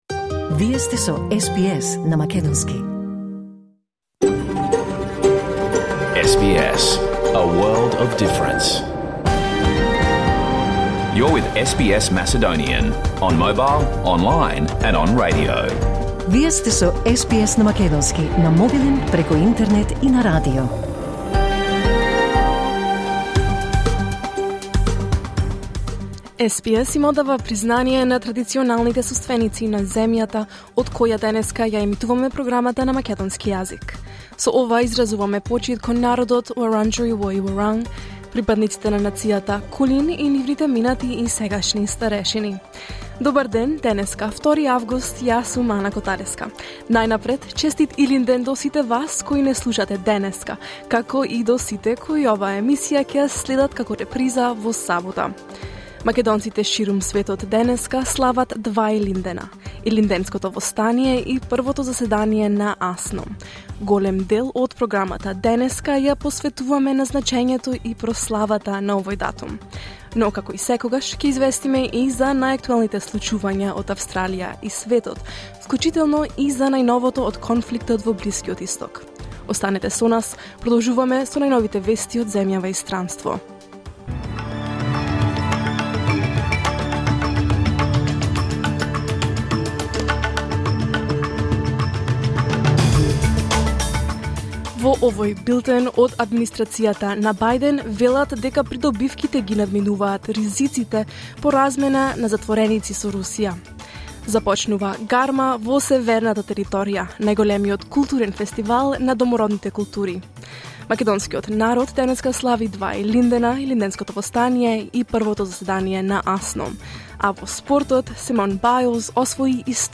Програмата на СБС на македонски емитувана во живо на 2 август 2024